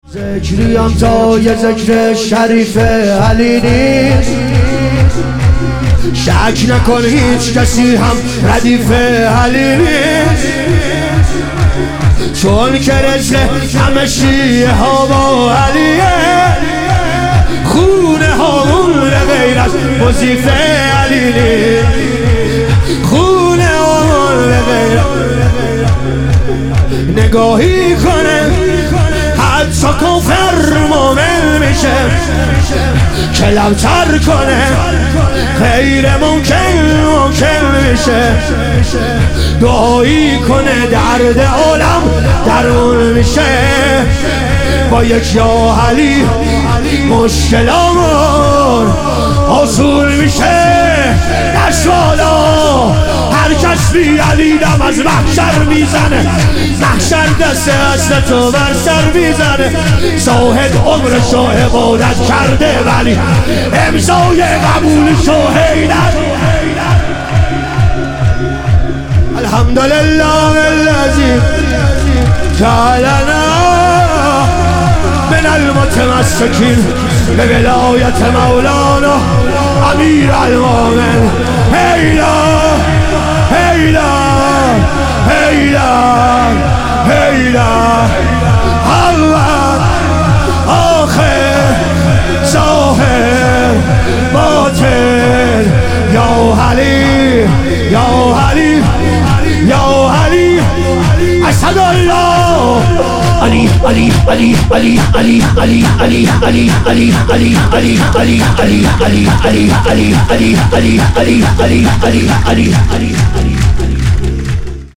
ظهور وجود مقدس امام جواد و حضرت علی اصغر علیهم السلام - شور